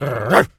dog_large_bark_03.wav